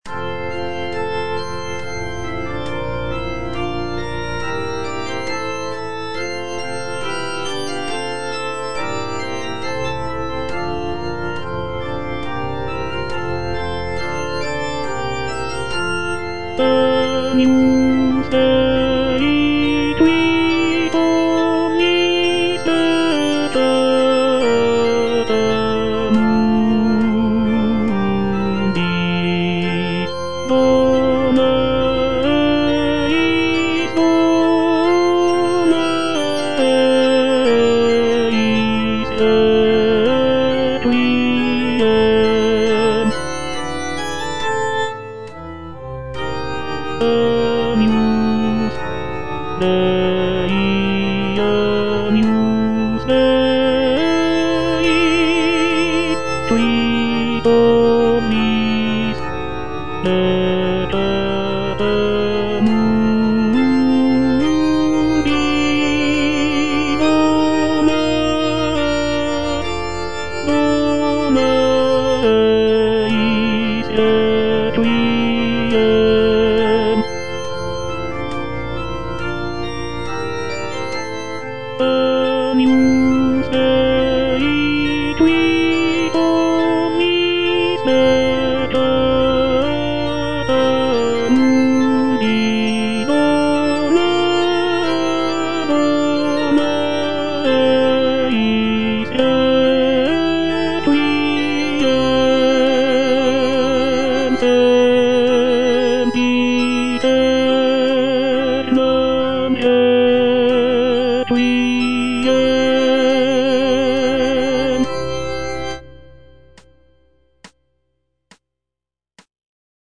G. FAURÉ - REQUIEM OP.48 (VERSION WITH A SMALLER ORCHESTRA) Agnus Dei (tenor I) (Voice with metronome) Ads stop: